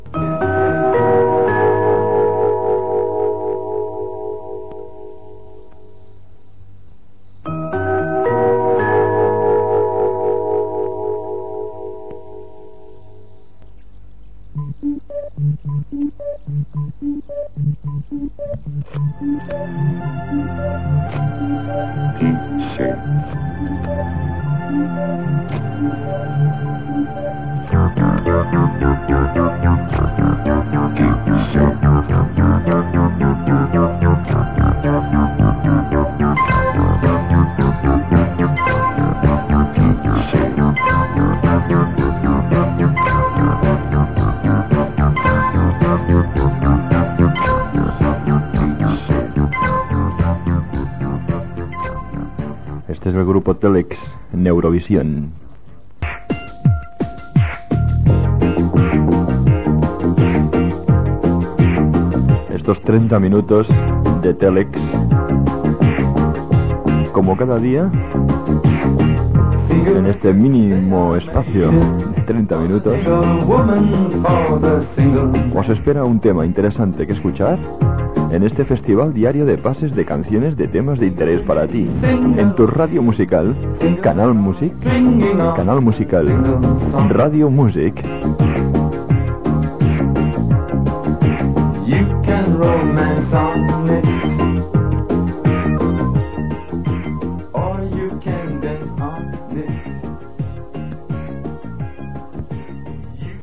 Sintonia, identificació i tema musical.